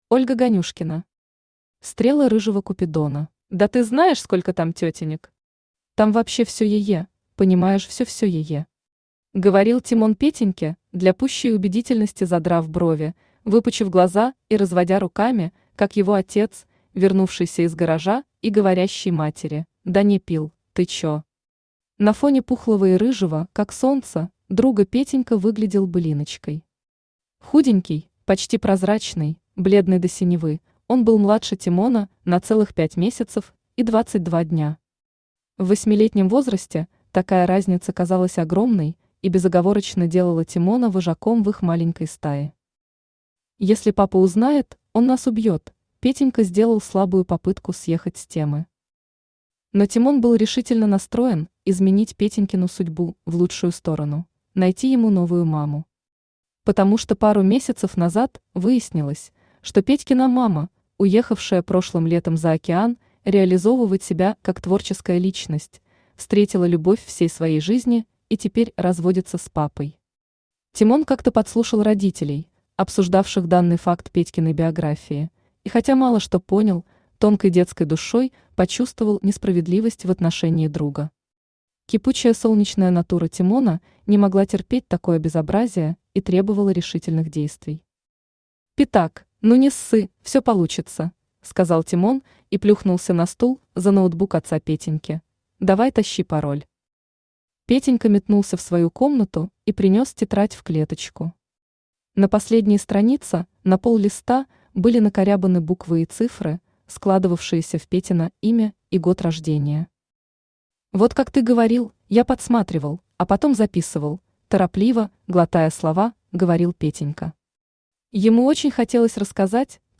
Аудиокнига Стрелы рыжего купидона | Библиотека аудиокниг
Aудиокнига Стрелы рыжего купидона Автор Ольга Станиславовна Ганюшкина Читает аудиокнигу Авточтец ЛитРес.